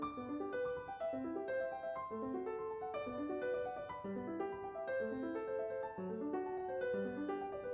例えば今回のモーツアルトソナタでは、左右が繋がってアルペジオのような美しい旋律を奏でる箇所があります。
右手の旋律と左手の旋律は、爽快でありながらも少しずつ遷移していてややこしく見えます。